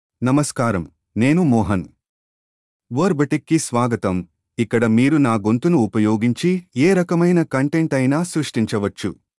Mohan — Male Telugu AI voice
Mohan is a male AI voice for Telugu (India).
Voice sample
Listen to Mohan's male Telugu voice.
Male
Mohan delivers clear pronunciation with authentic India Telugu intonation, making your content sound professionally produced.